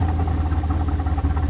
dviratel_loop.ogg